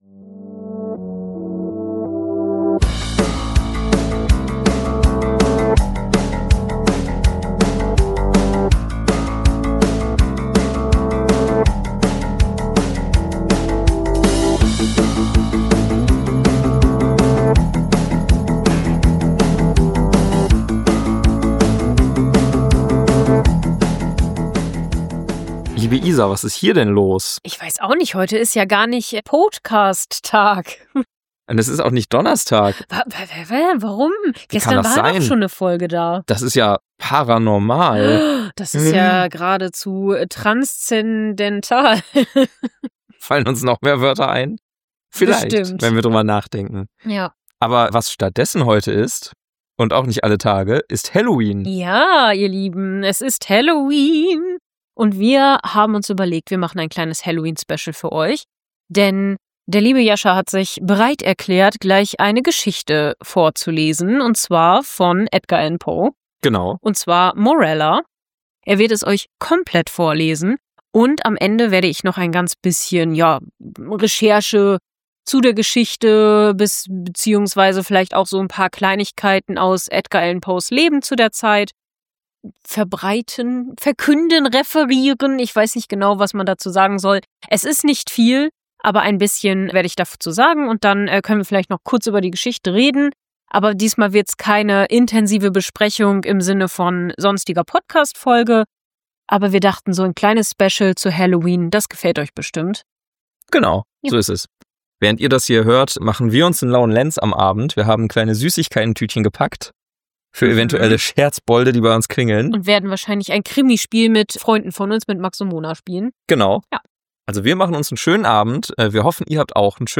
Wir lesen und besprechen die frühe Kurzgeschichte Morella, für die sich Edgar Allan Poe von der deutschen Schauerliteratur inspirieren lassen hat.